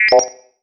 sci-fi_code_fail_01.wav